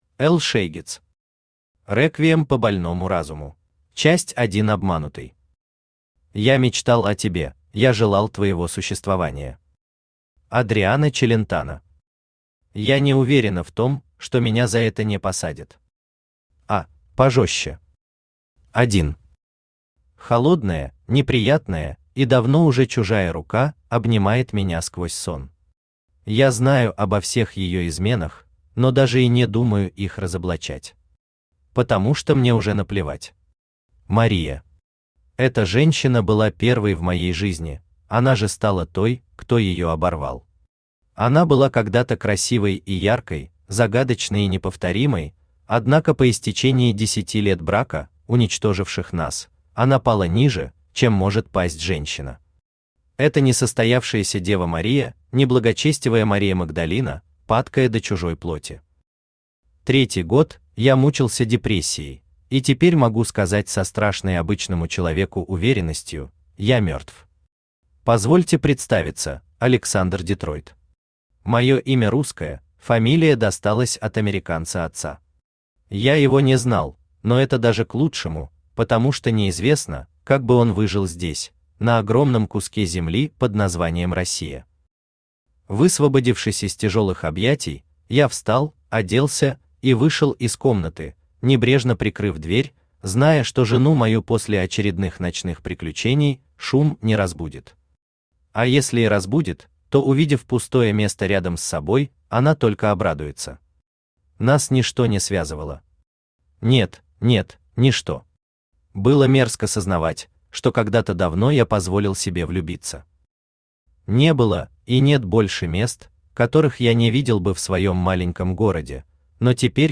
Аудиокнига Реквием по больному разуму | Библиотека аудиокниг
Aудиокнига Реквием по больному разуму Автор Эл Шейгец Читает аудиокнигу Авточтец ЛитРес.